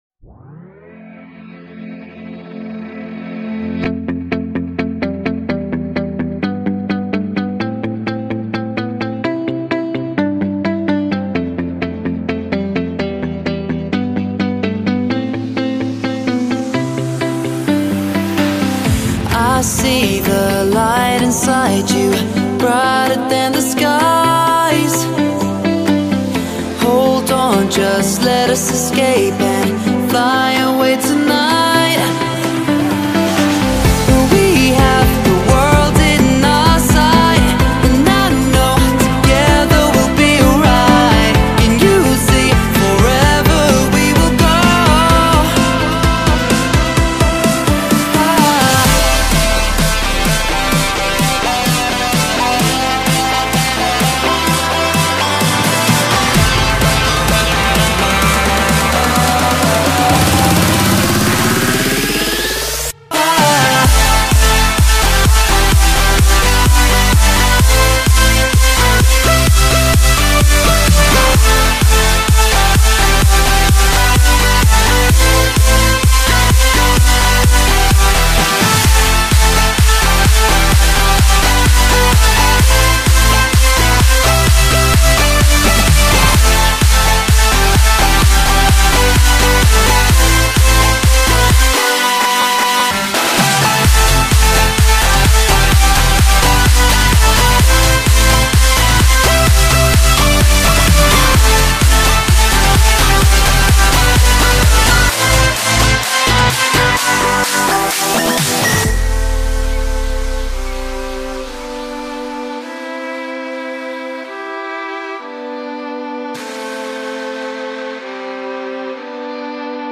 House, Hopeful, Euphoric, Energetic, Happy